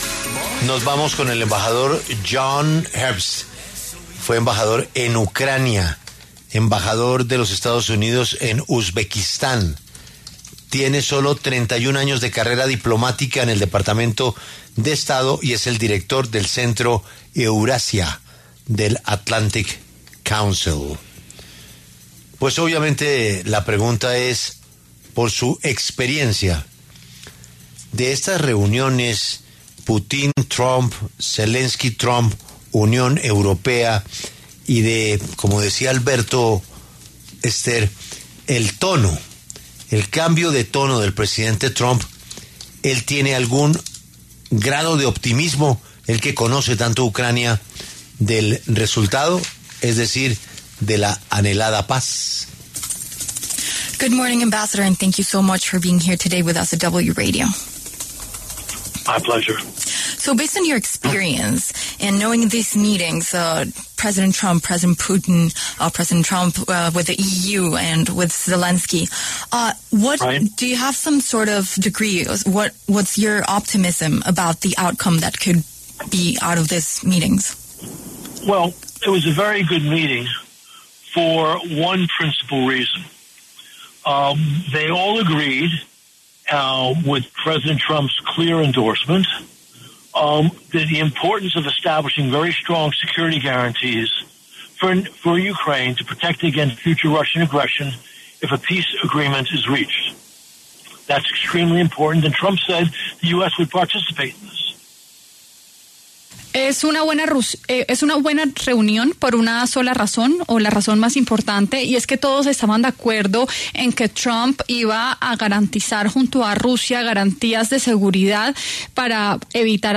Por esta razón, John Herbst, exembajador de Estados Unidos en Ucrania, habló en los micrófonos de La W, con Julio Sánchez Cristo.